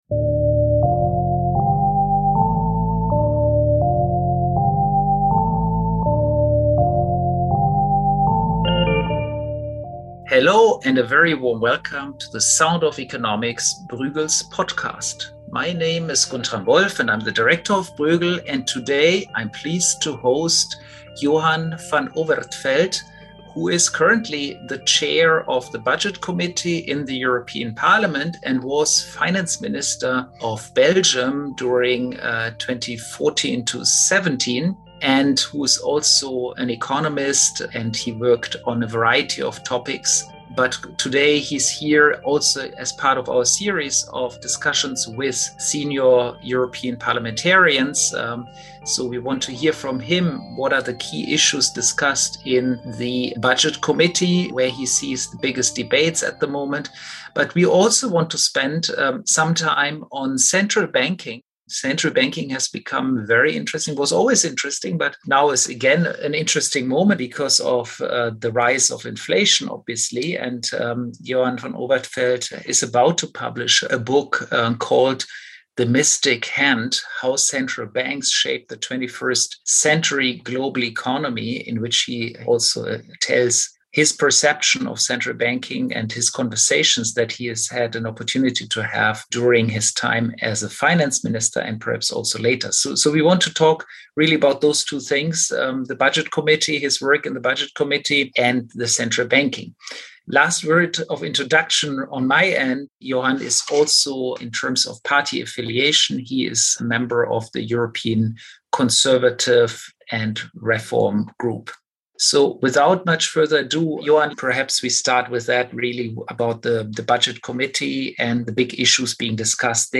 A conversation with Member of the European Parliament and Chair of the Committee on Budgets Johan Van Overtveldt.